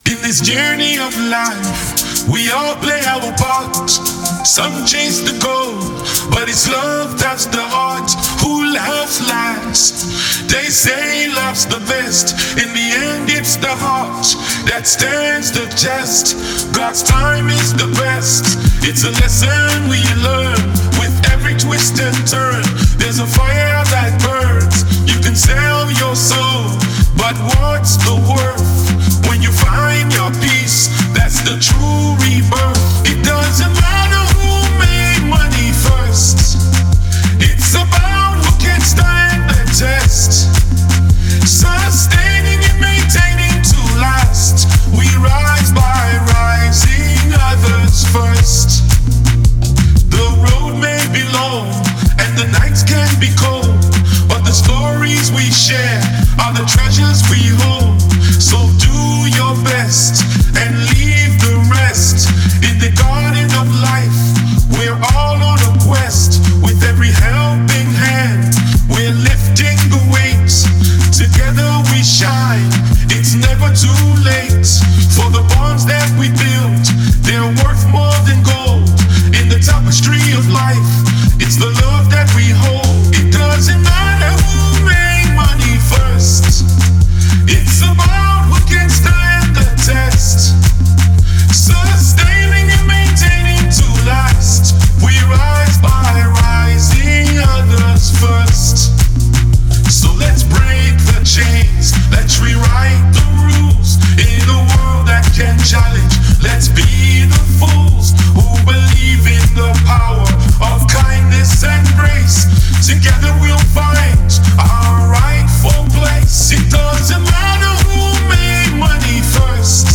Nigeria Music